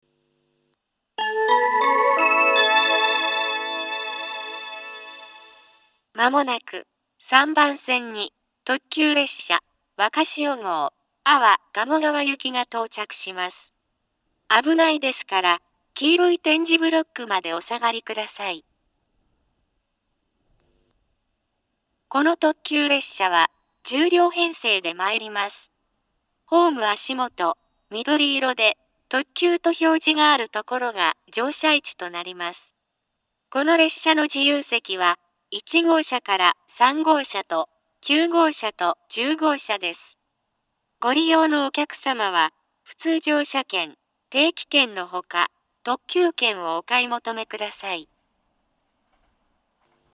３番線接近放送 特急わかしお号安房鴨川行（１０両）の放送です。
mobara-3bannsenn-sekkinn1.mp3